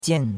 jian4.mp3